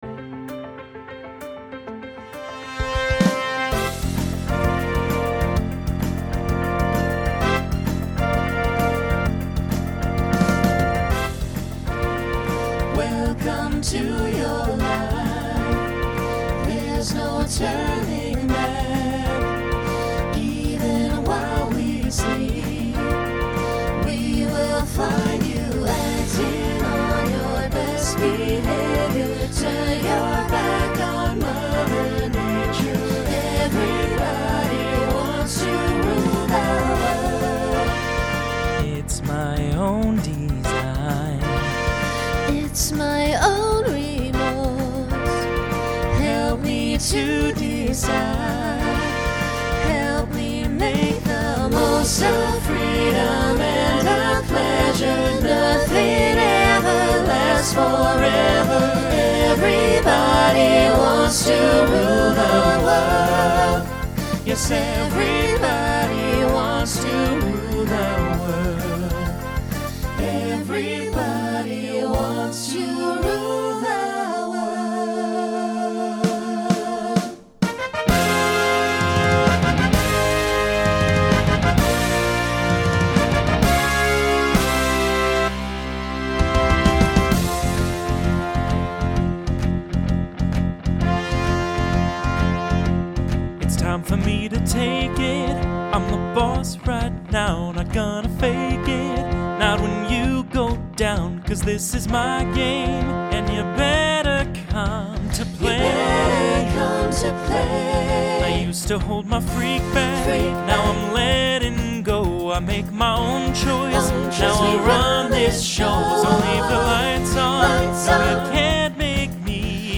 Instrumental combo Genre Pop/Dance , Rock
Voicing SATB